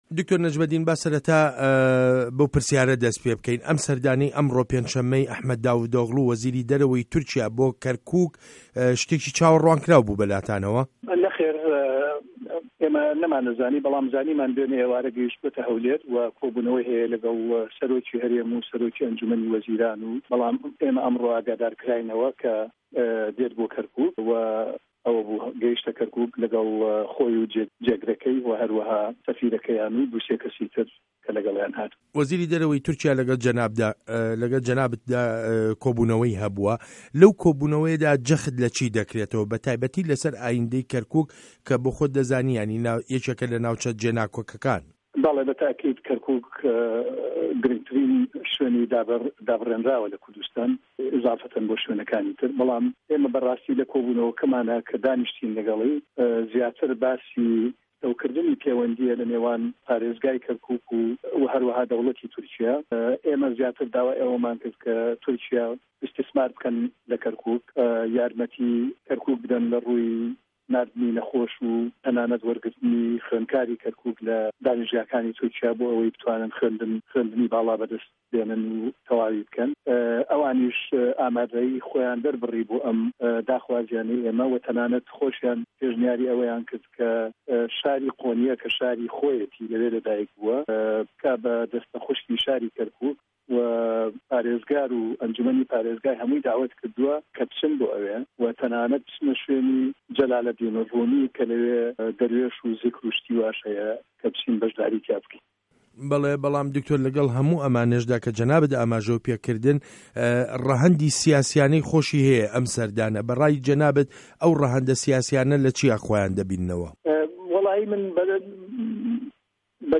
وتووێژ له‌گه‌ڵ دکتۆر نه‌جمه‌دین که‌ریم